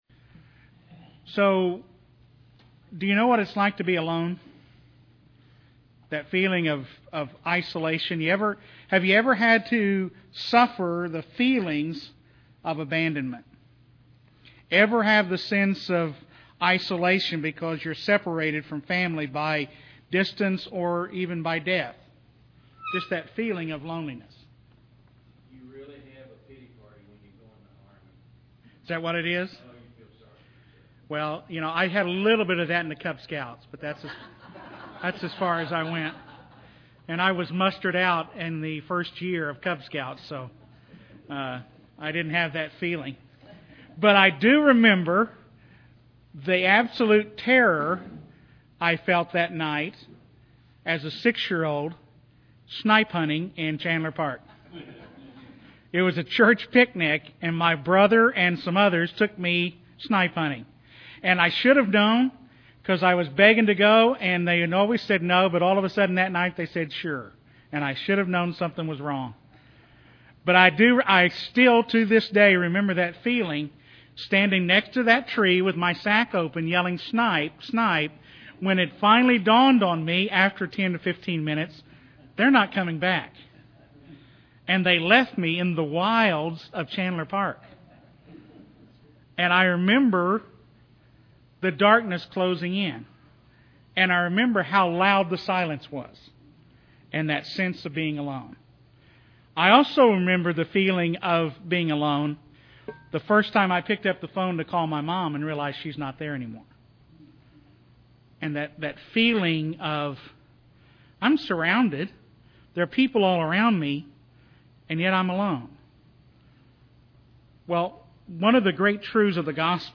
March 9th 2011, Wednesday Bible Study - TBCTulsa